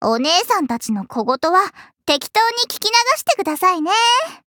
文件 文件历史 文件用途 全域文件用途 Ja_Fifi_amb_04.ogg （Ogg Vorbis声音文件，长度4.5秒，102 kbps，文件大小：56 KB） 源地址:游戏语音 文件历史 点击某个日期/时间查看对应时刻的文件。 日期/时间 缩略图 大小 用户 备注 当前 2018年5月25日 (五) 02:12 4.5秒 （56 KB） 地下城与勇士  （ 留言 | 贡献 ） 分类:祈求者比比 分类:地下城与勇士 源地址:游戏语音 您不可以覆盖此文件。